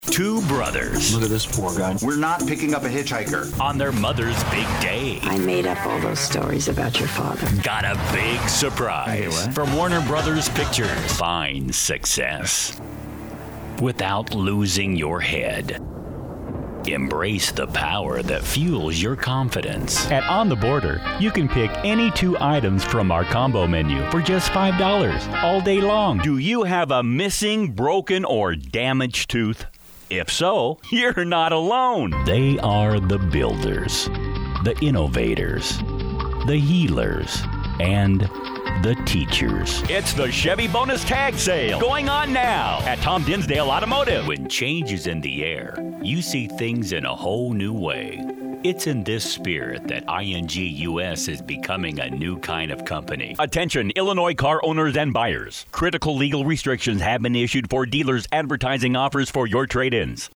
Fun, Energetic, Authoritative Available Now For Commercial Voiceover